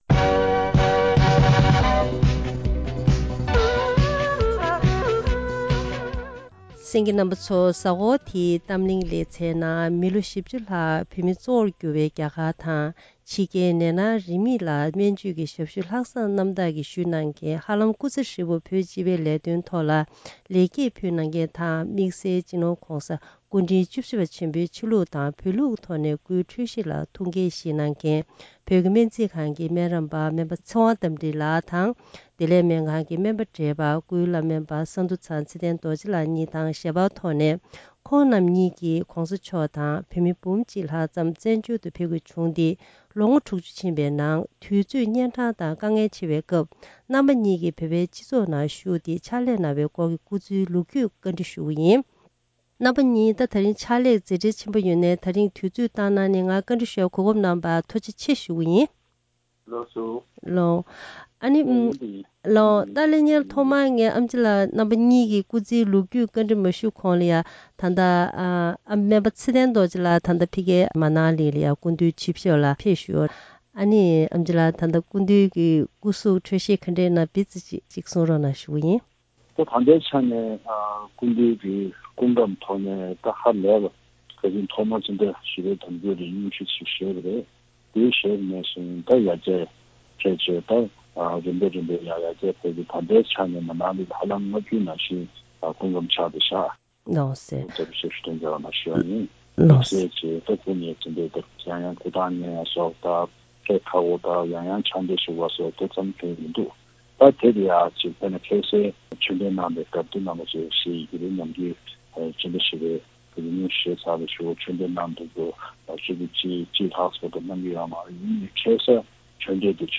གཏམ་གླེང་གི་ལེ་ཚན་ནང་།